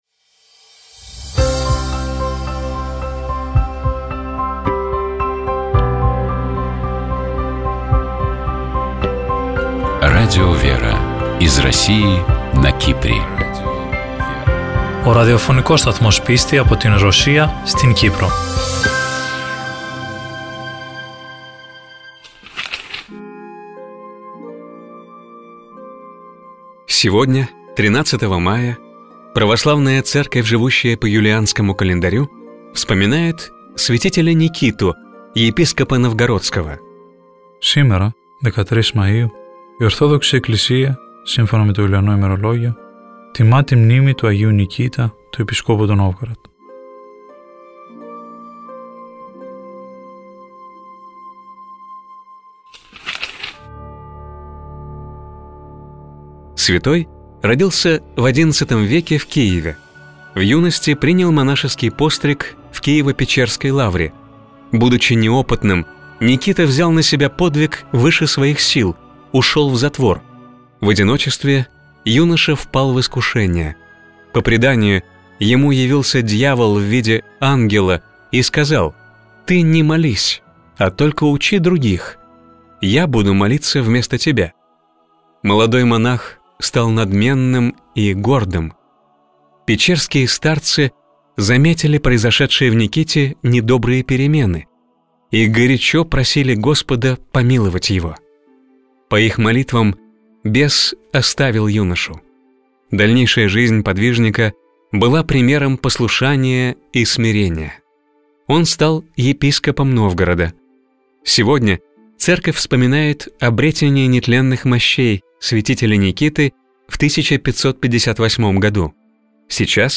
У нас в гостях была актриса, режиссер, Народная артистка России Елена Цыплакова. Наша гостья рассказала о своем творческом пути, о приходе к вере, и о тех встречах и людях, которые повлияли на ее творчество и на приход к Богу.